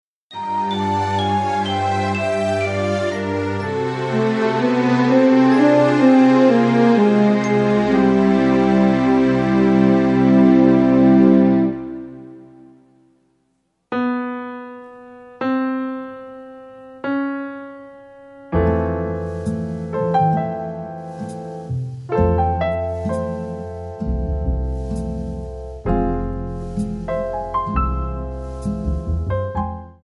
MPEG 1 Layer 3 (Stereo)
Backing track Karaoke
Pop, Jazz/Big Band, 1980s